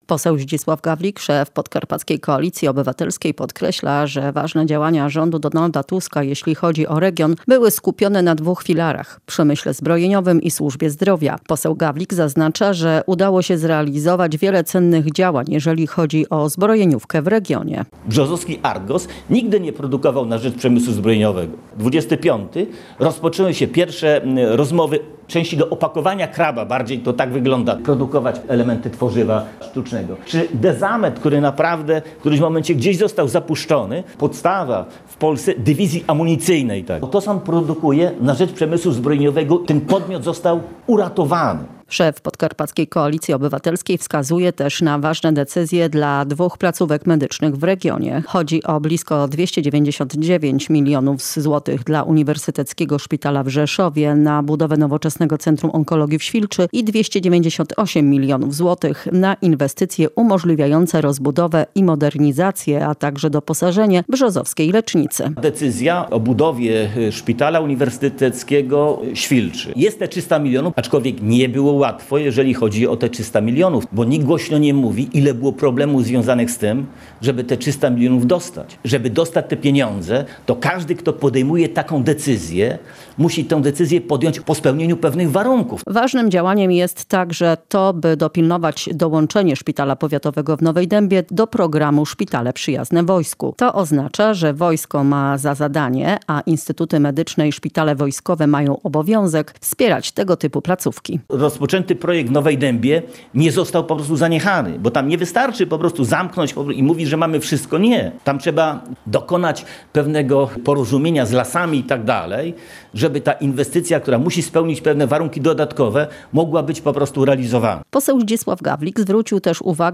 To dwa ważne obszary wspierane przez rząd Donalda Tuska jeżeli chodzi o nasz region – mówi poseł Zdzisław Gawlik.
Relacja